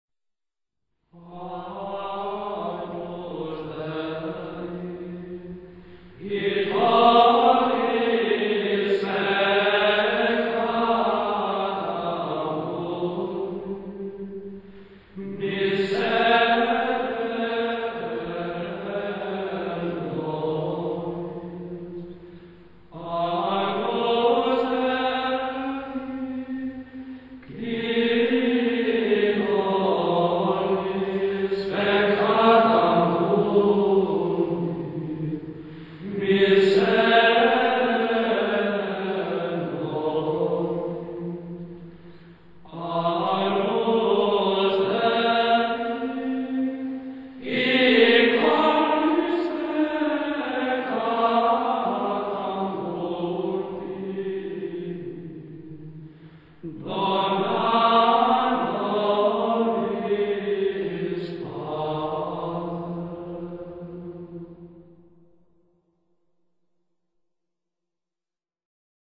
Monjes del Monasterio Benedictino de Danto Domingo de Silos – El Alma del Gregoriano
Исполнитель: братья-бенедиктинцы монастыря Санто Доминго де Силос. 1956-62 гг.